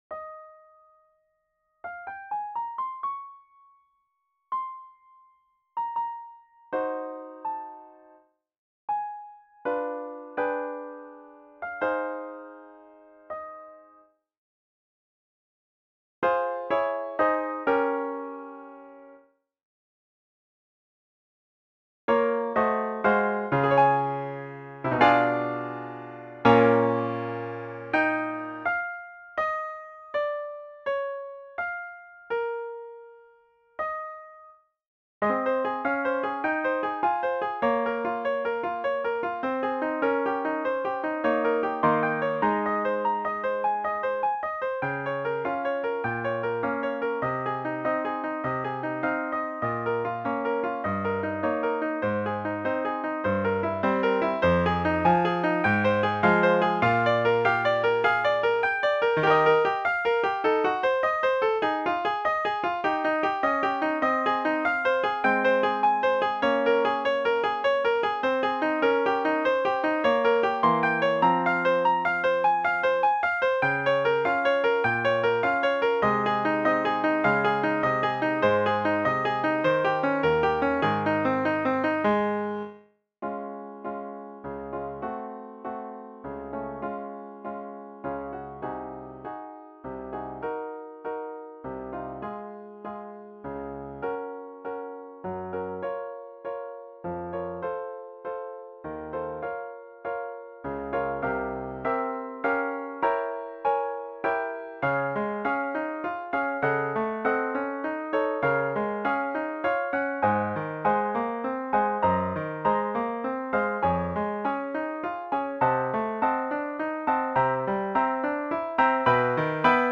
NOCTURNE.
For Pianoforte.
nocturne.mp3